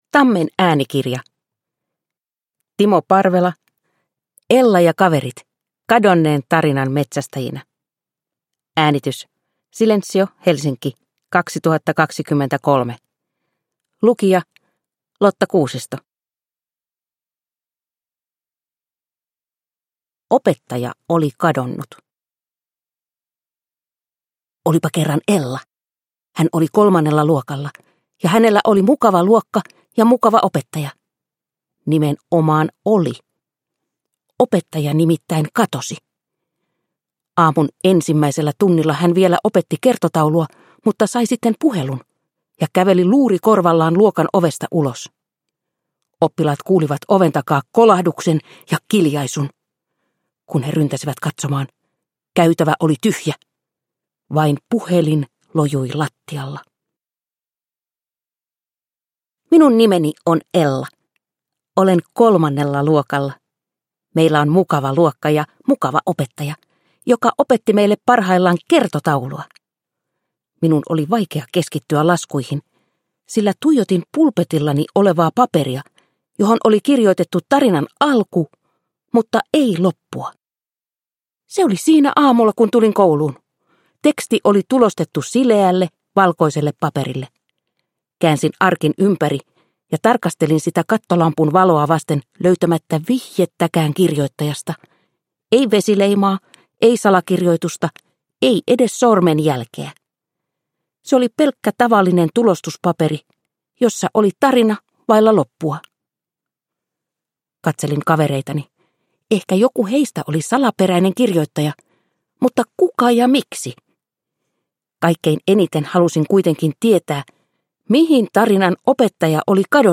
Ella ja kaverit kadonneen tarinan metsästäjinä – Ljudbok – Laddas ner